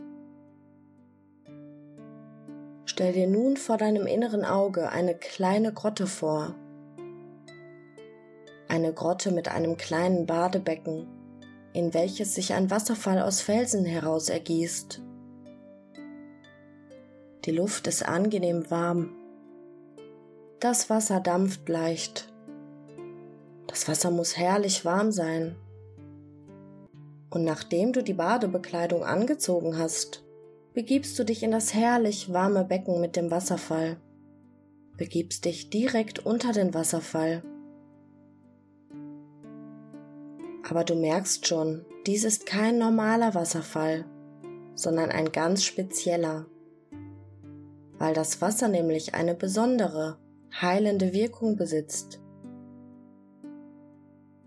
Erleben Sie die regenerative Kraft der Hypnose
Sprecherin
E3012-Entspannung-Blockadenloesung-Bad-in-der-Grotte-Hoerprobe.mp3